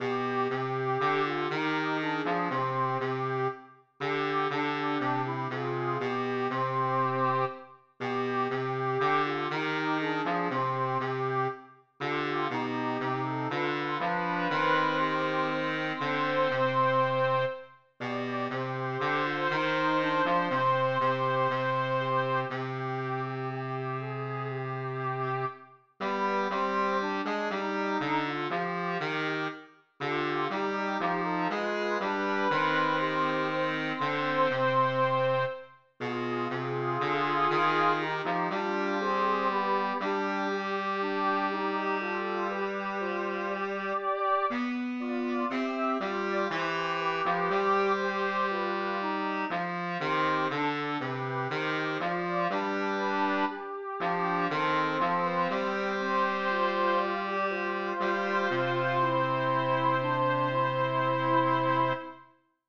Besetzung: SAB
Schwierigkeit: fast leicht